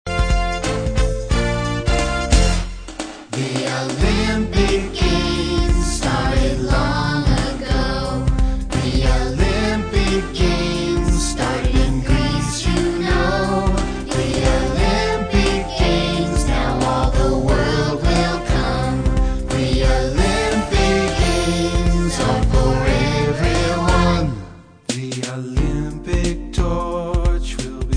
Song for Young Children